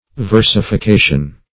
Versification \Ver`si*fi*ca"tion\, n. [L. versificatio: cf. F.